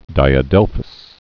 (dīə-dĕlfəs)